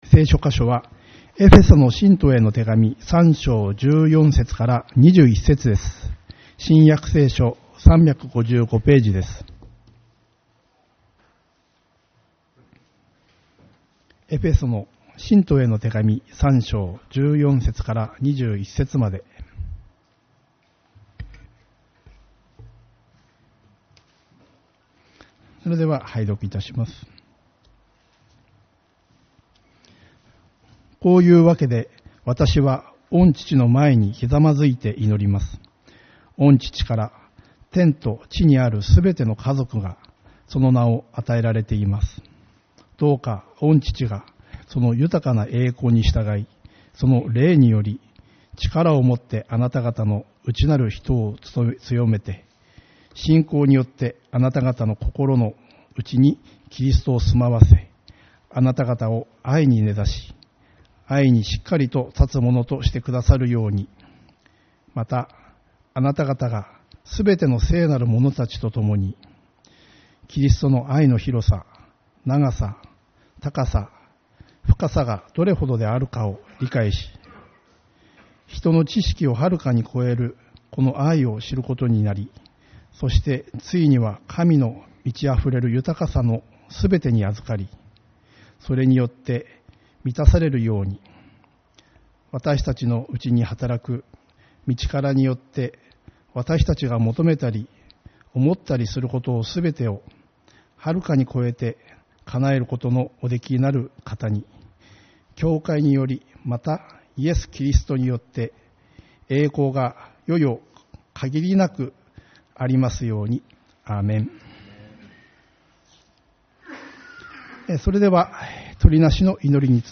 8月4日主日礼拝